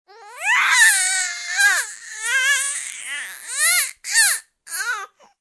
babyscream1.ogg